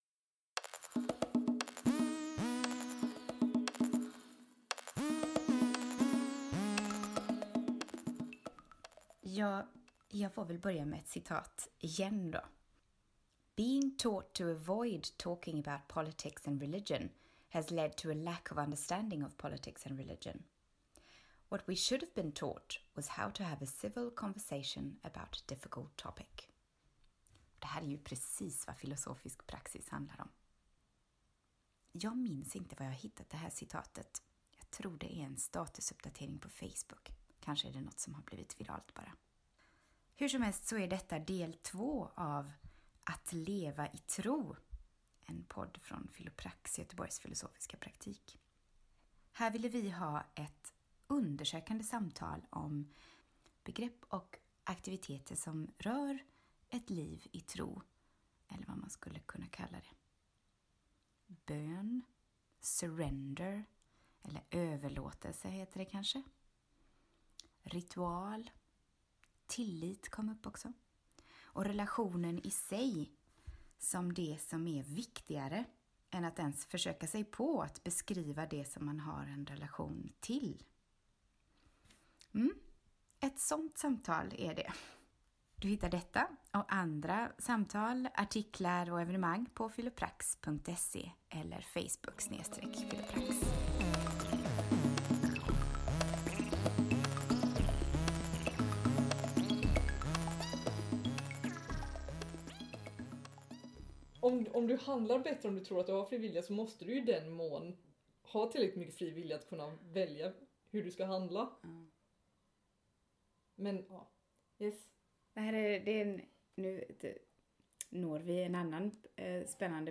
Ett enda långt samtal, uppdelat i två avsnitt.